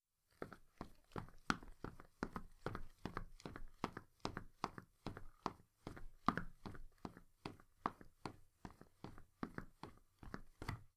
running-shoes-1.wav